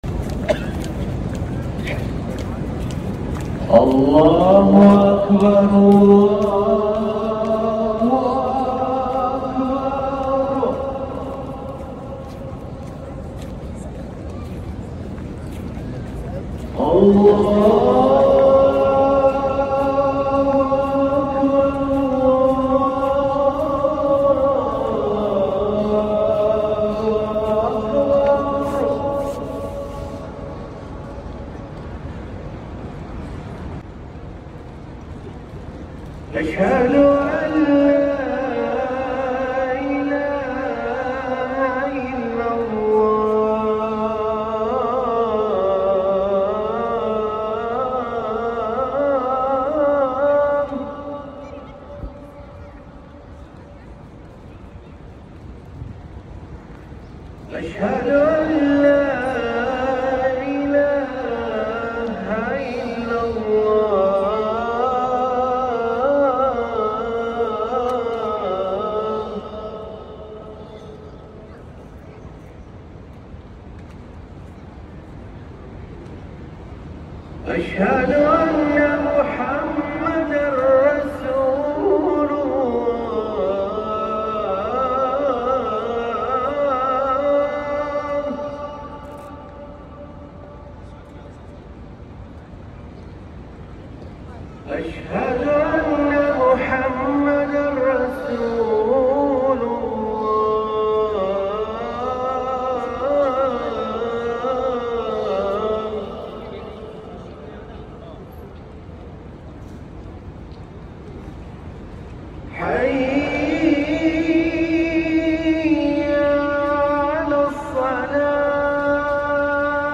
الأذان الأول لصلاة الفجر > ركن الأذان 🕋